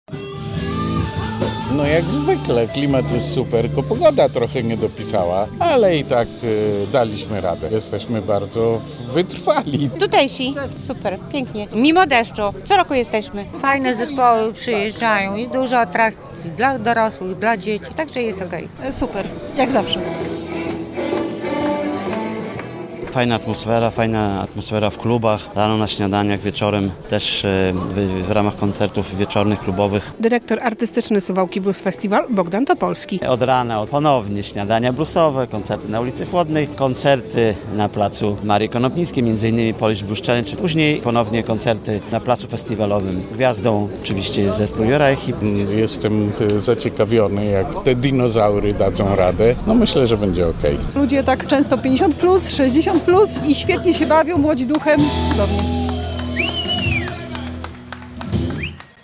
Trzeci dzień festiwalu - relacja